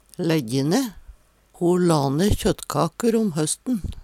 lejje ne - Numedalsmål (en-US)
DIALEKTORD